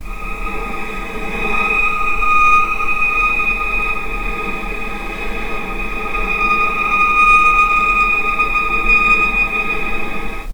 vc_sp-D#6-pp.AIF